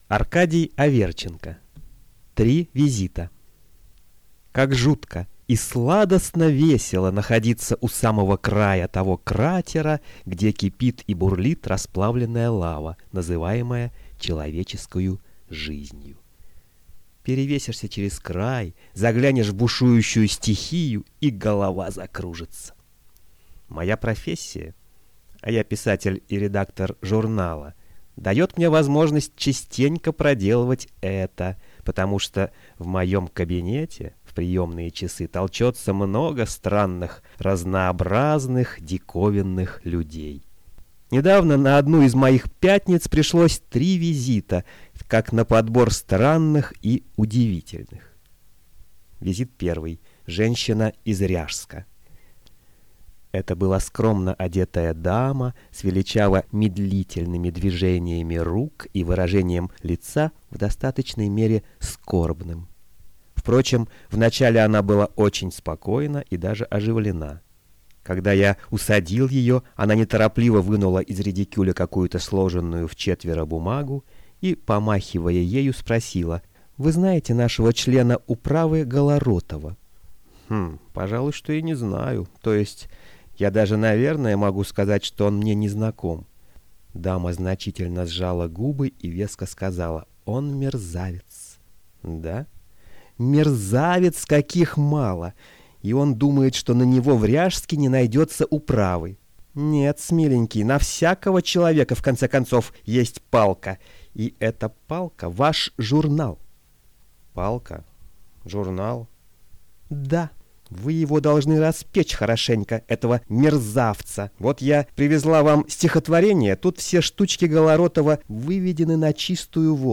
Аудиокнига Три визита | Библиотека аудиокниг